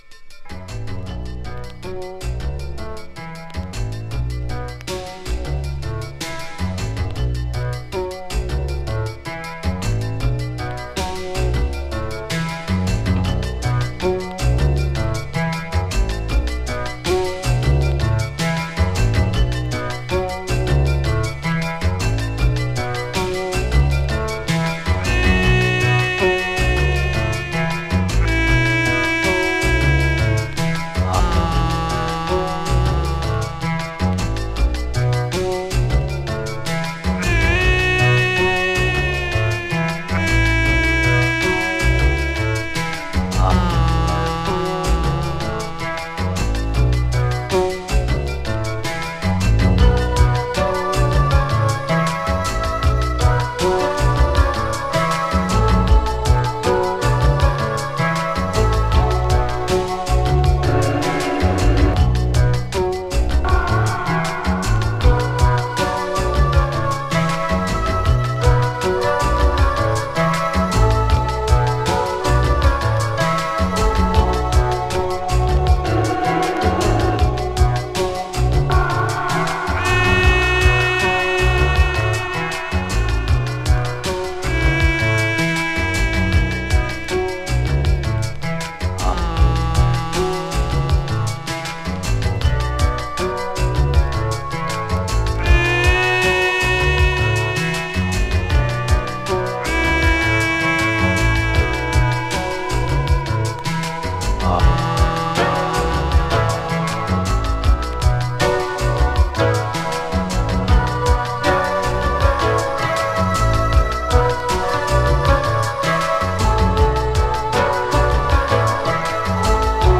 DEEPで幻想的な